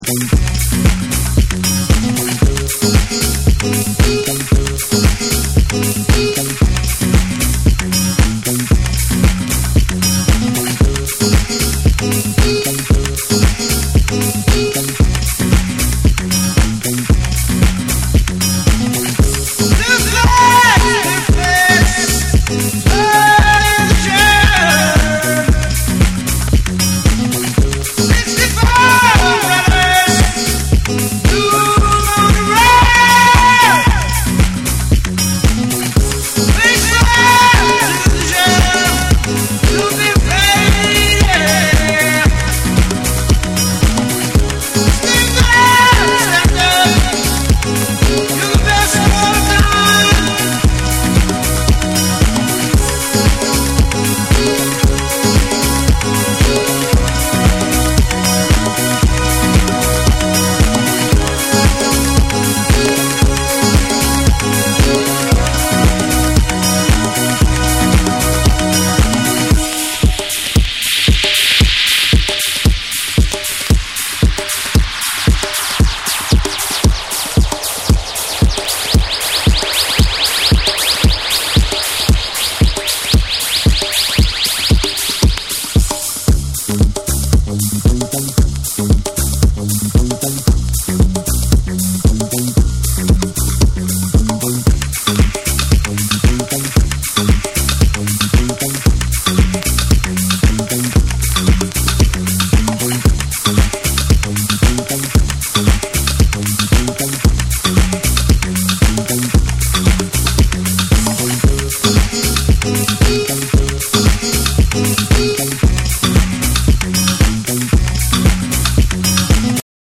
format : 12inch
JAPANESE / BREAKBEATS